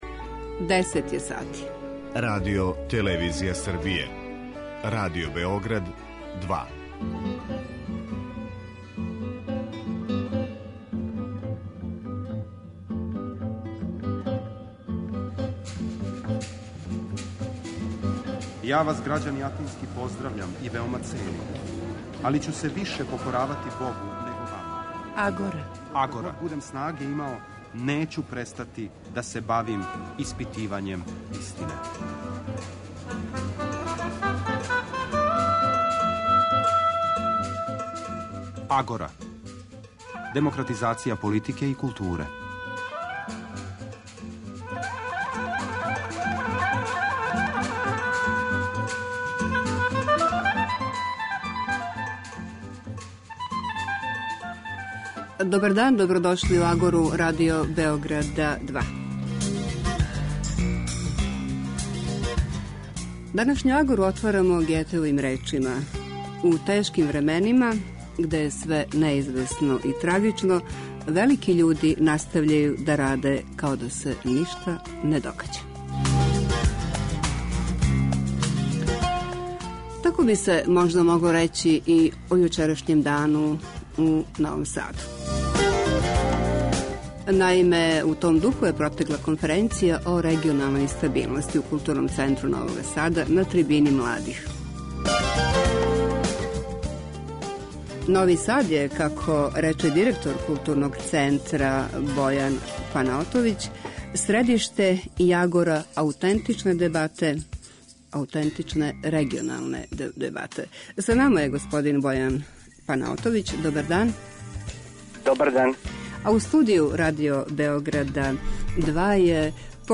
Политички магазин